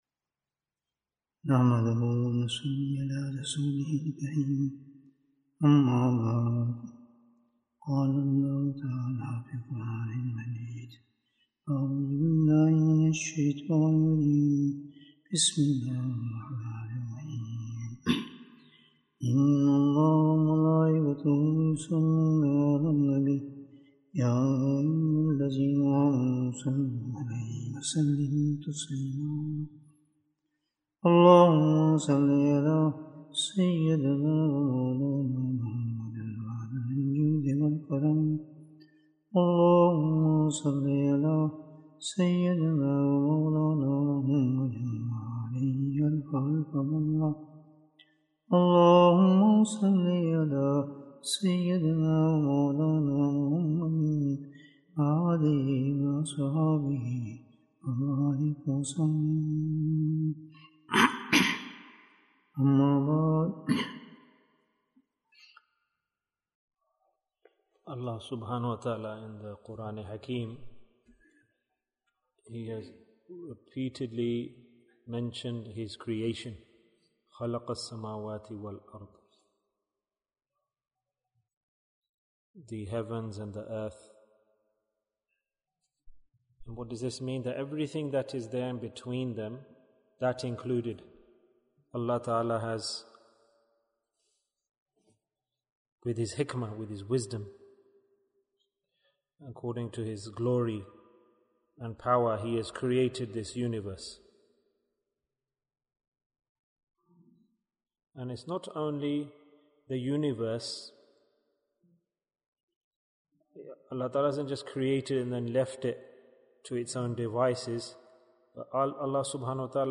Bayan, 73 minutes3rd November, 2022